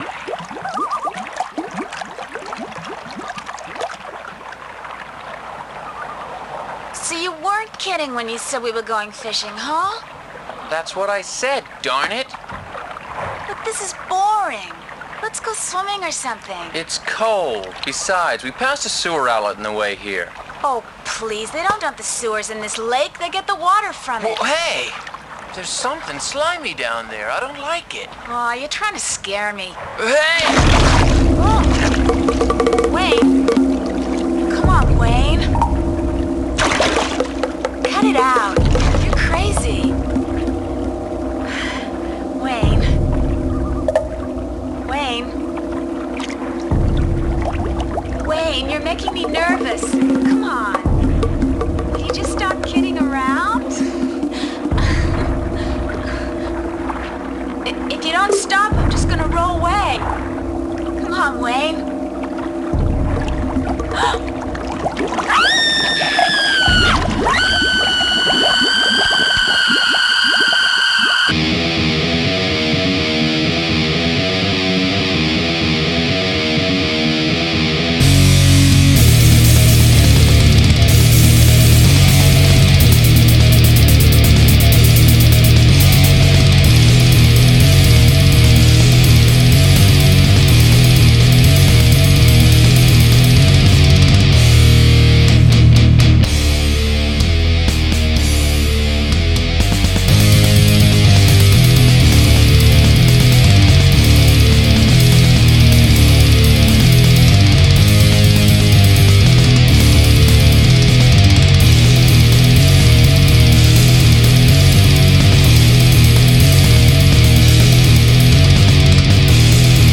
Heavy metal/Death metal demo made using only iPad
I appreciate that almost everyone here will have absolutely no interest in this kind of noisy music but I felt compelled to post this, mostly due to my sheer amazement of what a simple iPad is capable of making.
Below is a demo song for my Death metal band that has being made entirely on iPad. By that I mean there has been no additional instruments used whatsoever.
I use just 3 basic tracks: Bass, Drums and guitar.